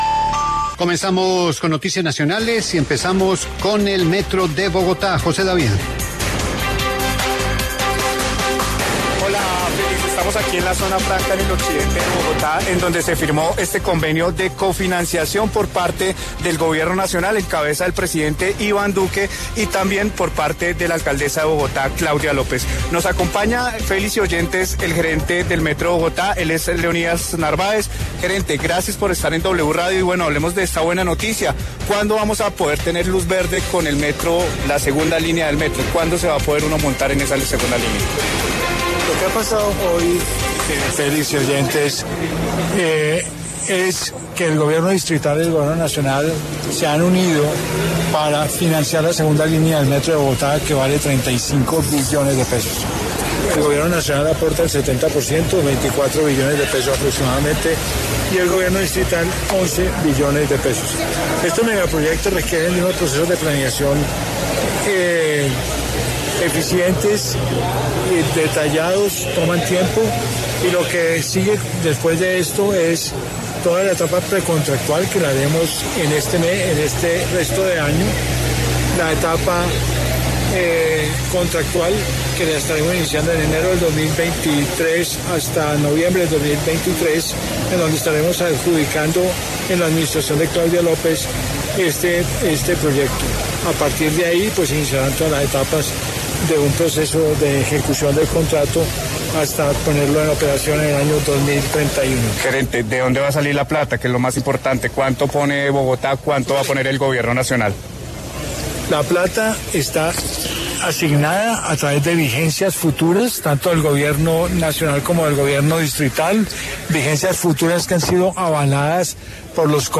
El gerente del Metro de Bogotá, Leonidas Narváez, dijo en diálogo con W Radio que el paso a seguir es la etapa contractual, cuyo proceso se estará adjudicando en la Alcaldía de Claudia López.